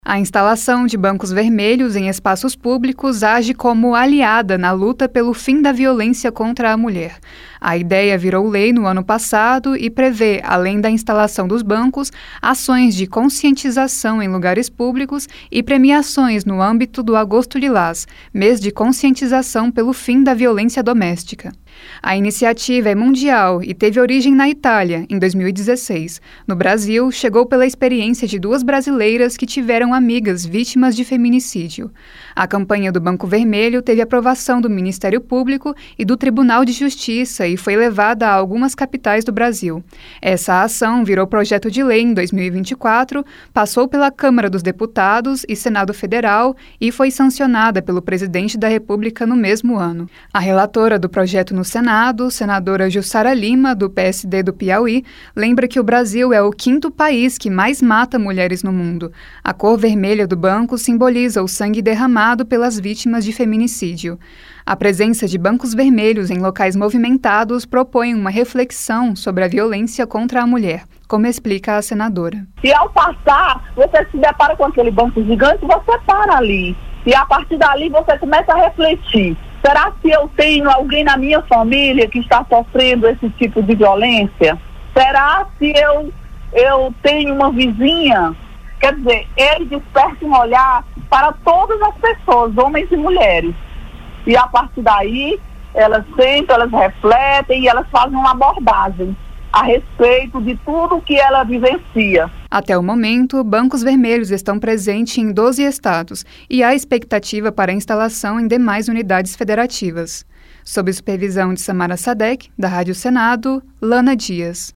A senadora Jussara Lima (PSD-PI), relatora da proposta no Senado, explica como a ideia chama atenção para o tema.